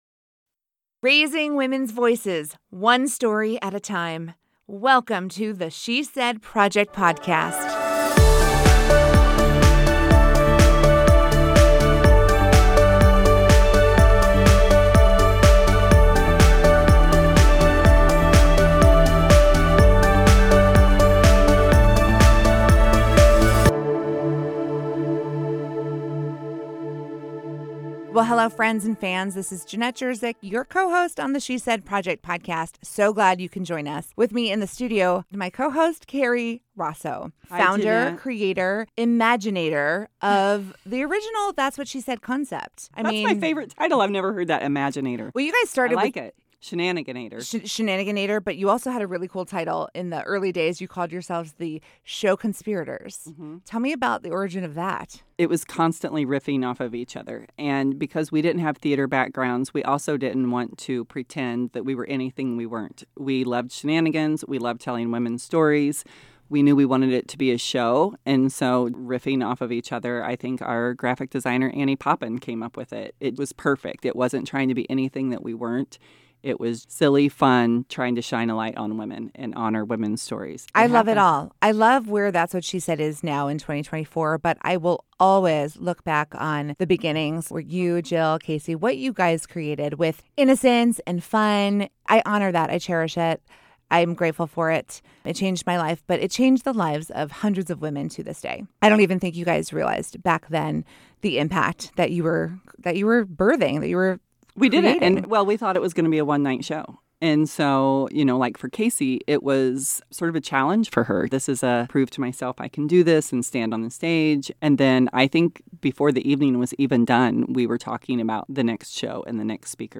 The She Said Project Podcast is recorded in partnership with Illinois Public Media.